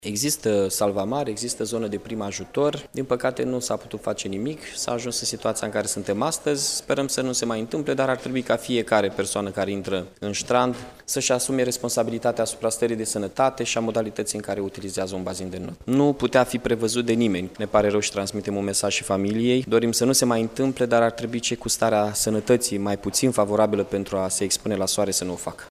După decesul unui tânăr la Ștrandul Municipal, primarul interimar, Mihai Chirica a făcut un apel la persoanele care se știu cu afecțiuni ale aparatului cardiorespirator să evite scăldatul în zilele caniculare: